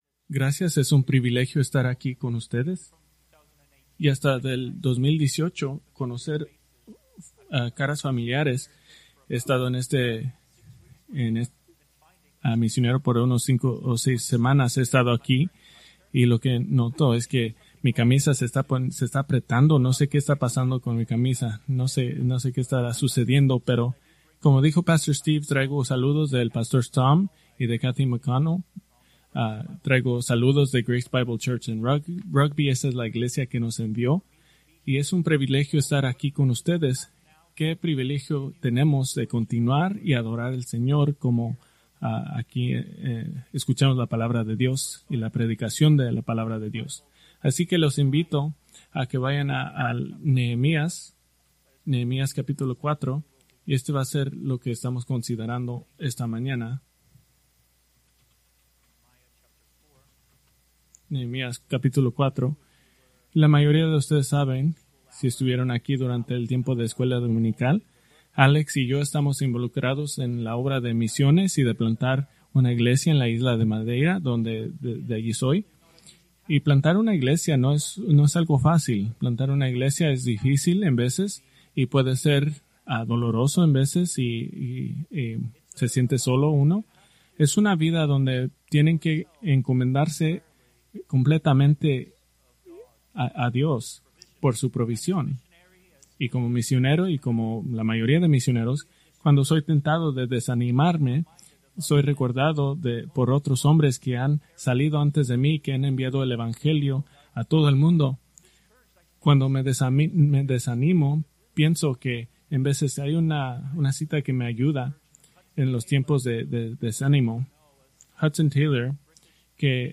Preached March 1, 2026 from Nehemías 4:1-6